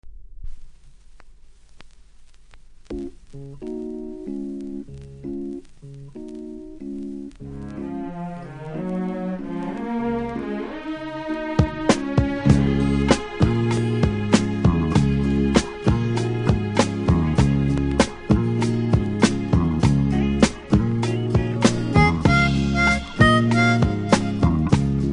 盤に曇りありプレス起因か両面序盤ノイズありますので試聴で確認下さい。